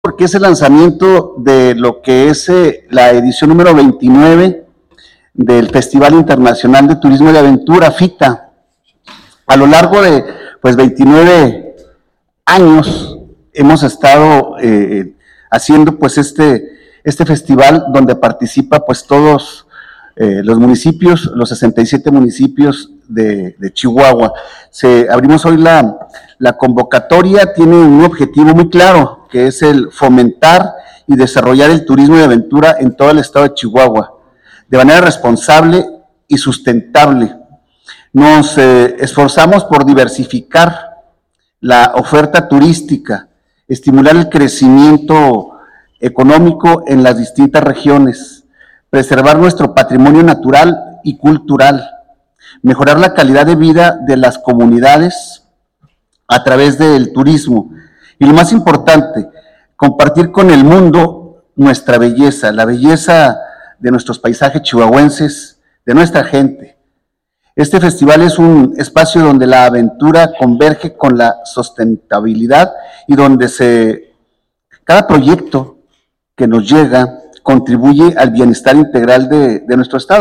AUDIO: EDIBRAY GÓMEZ, SECRETARIO DE TURISMO DEL ESTADO